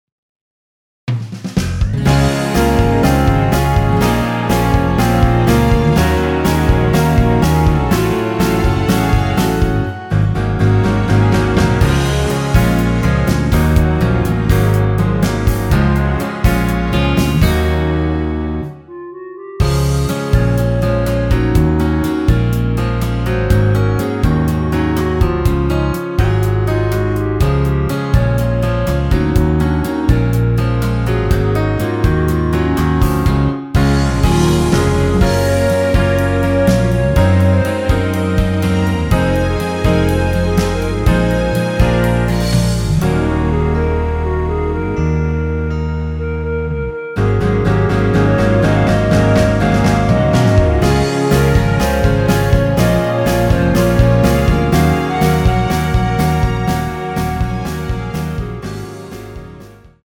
원키에서(-2)내린 멜로디 포함된 MR 입니다.
Db
앞부분30초, 뒷부분30초씩 편집해서 올려 드리고 있습니다.
(멜로디 MR)은 가이드 멜로디가 포함된 MR 입니다.